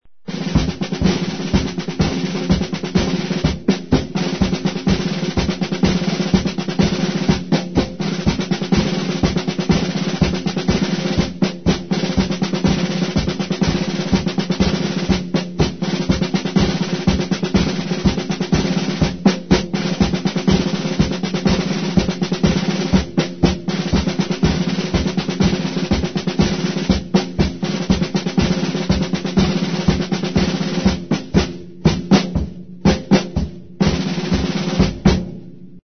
DESFILE MILITAR DRUM MILITAR
Ambient sound effects
Descargar EFECTO DE SONIDO DE AMBIENTE DESFILE MILITAR DRUM MILITAR - Tono móvil
Desfile_militar_DRUM_militar.mp3